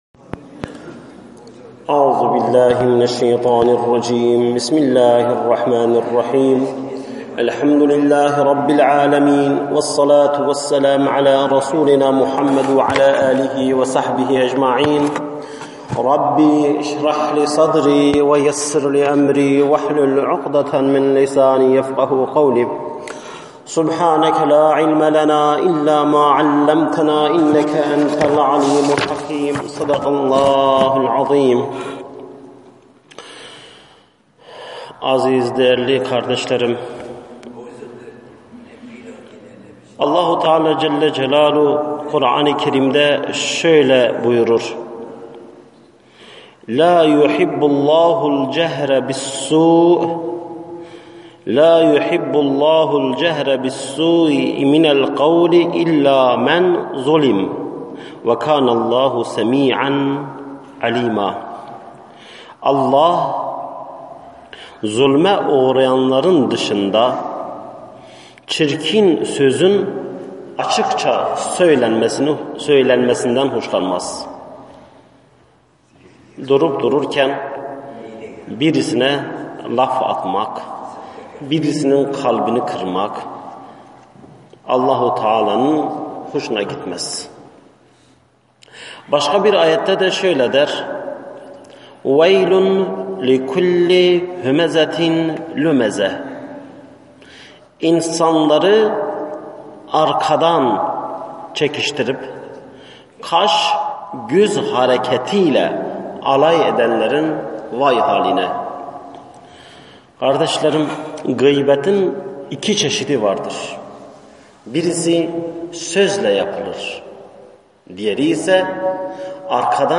SESLİ SOHBETLER